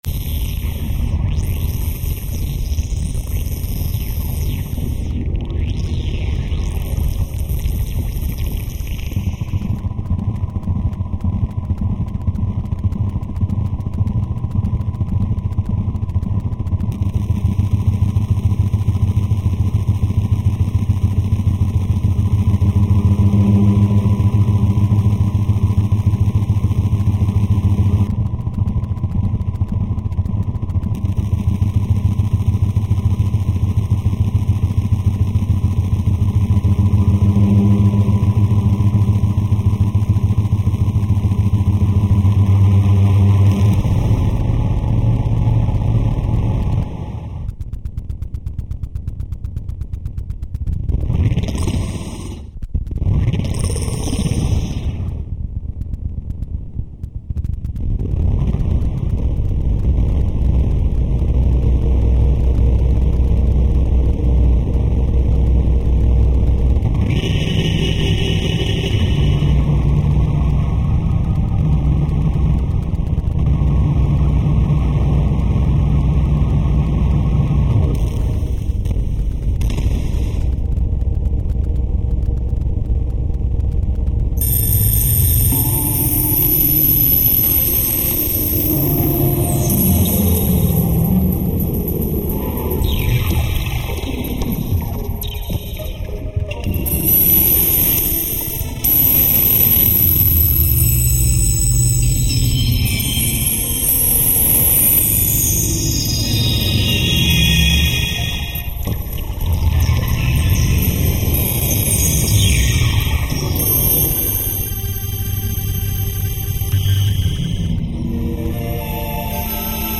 File under: Dada / Power Electronics